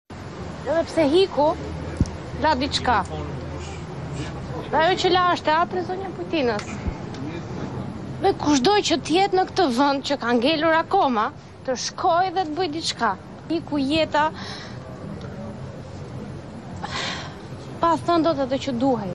Me zërin që i dridhej sound effects free download